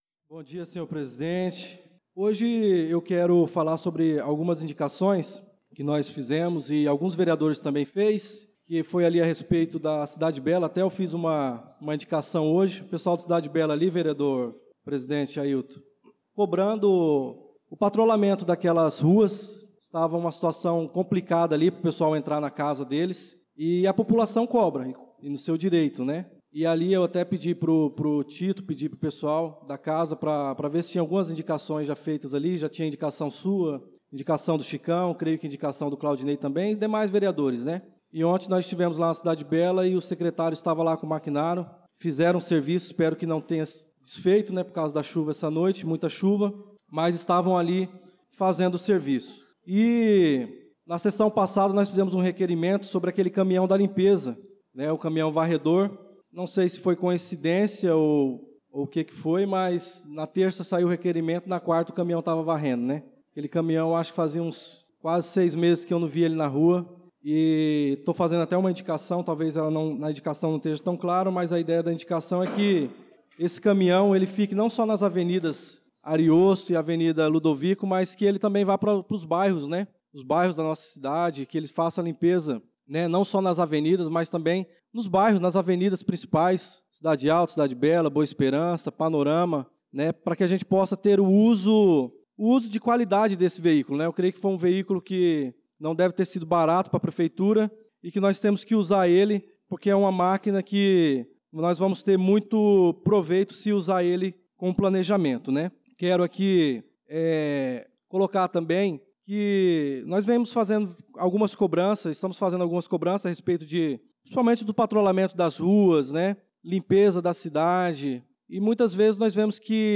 Pronunciamento do vereador Darlan Carvalho na Sessão Ordinária do dia 25/03/2025